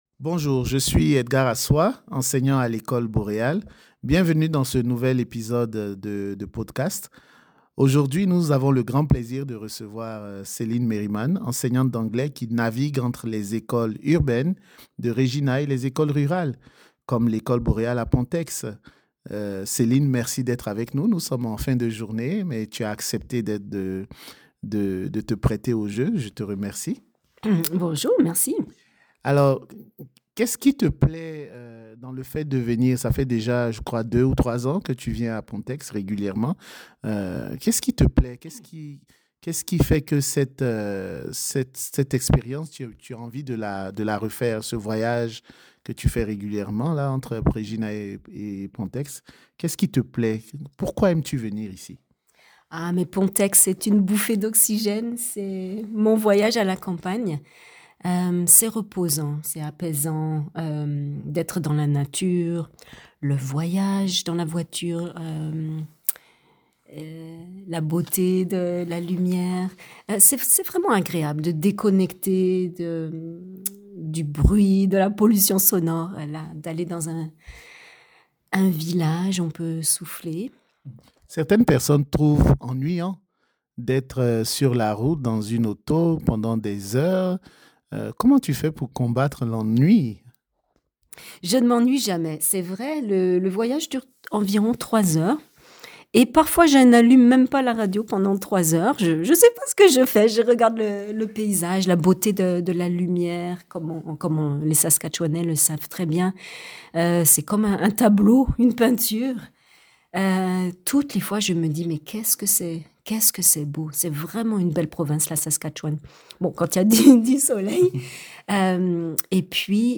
Dans cette entrevue